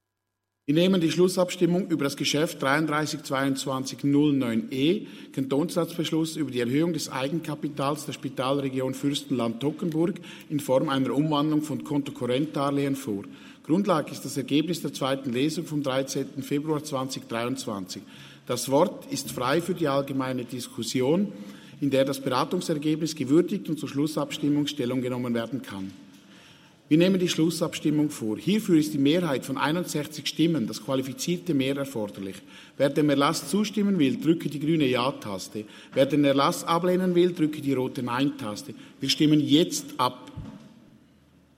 Session des Kantonsrates vom 13. bis 15. Februar 2023, Frühjahrssession